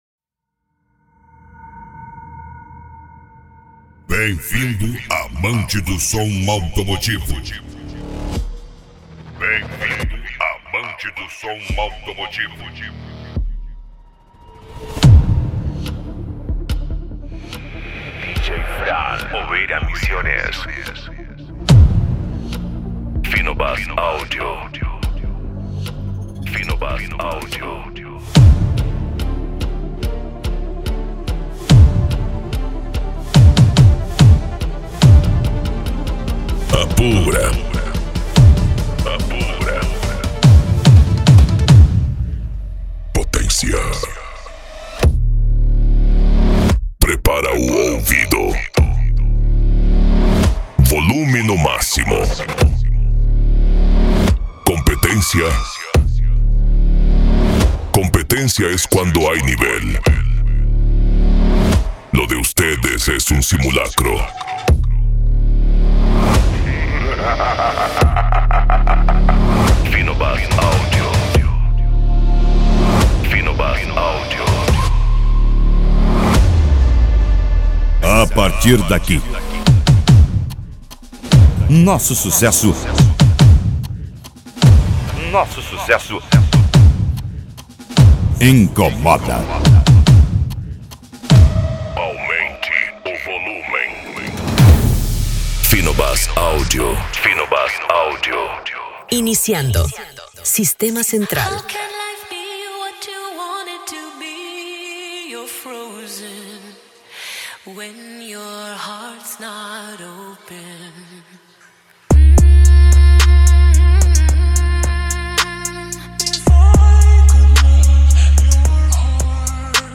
Bass
PANCADÃO